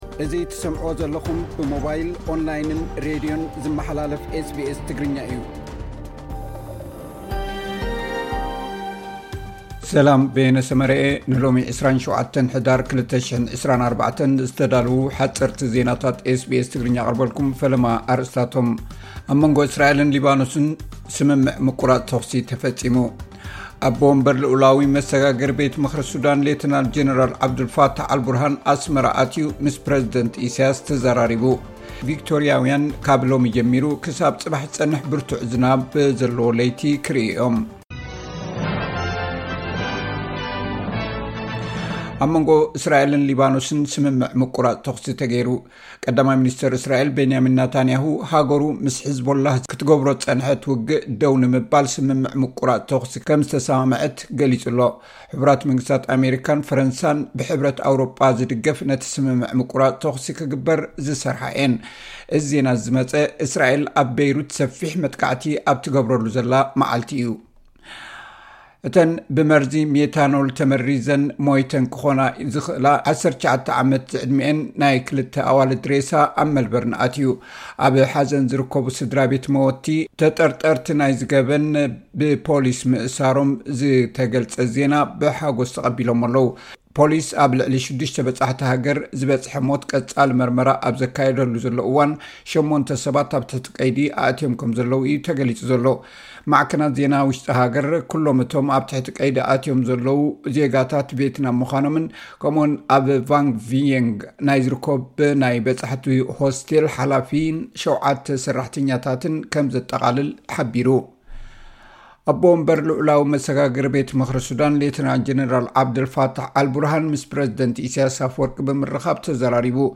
ሓጸርቲ ዜናታት ኤስ ቢ ኤስ ትግርኛ (27 ሕዳር 2024)